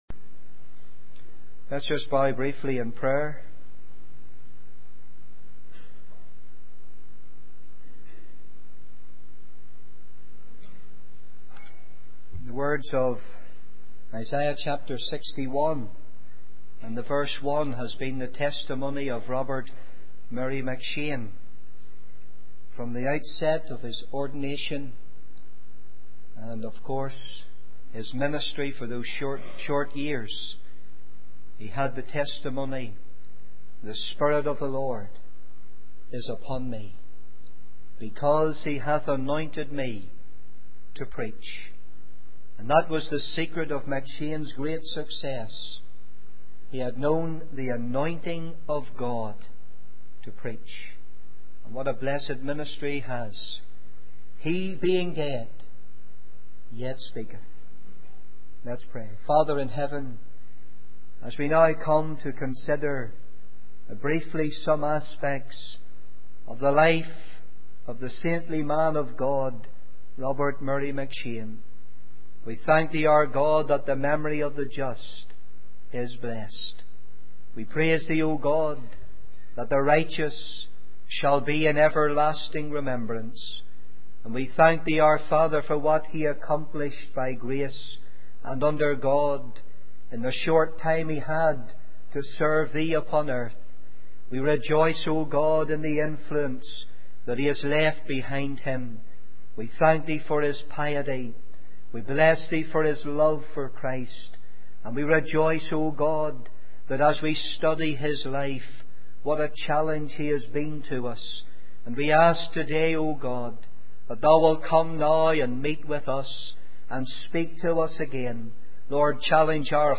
In this sermon, the speaker shares the story of a church that started praying fervently for the work of God, for backslidden members, and for individuals. One man's simple prayer, asking God to give him back his tears, became the catalyst for a powerful revival in the church. The speaker emphasizes the importance of genuine compassion and sympathy that melts our hearts to tears, rather than hyped-up emotions.